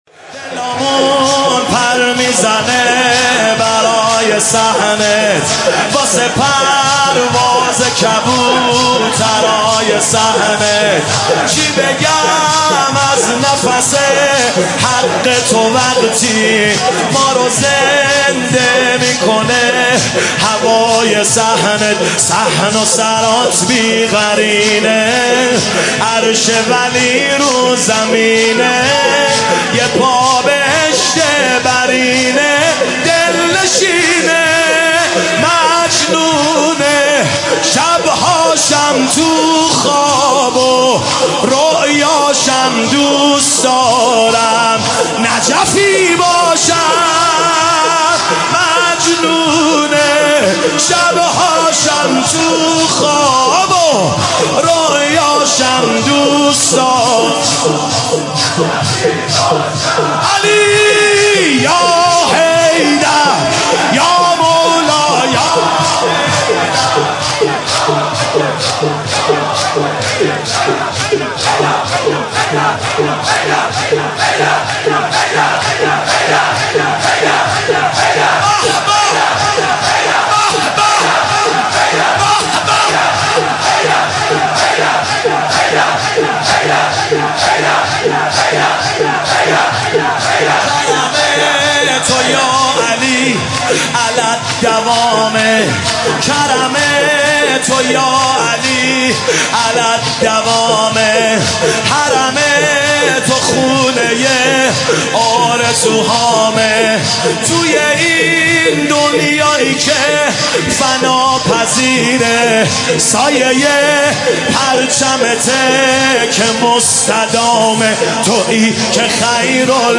شب نهم تاسوعا محرم 96/07/7
مداحی اربعین
شور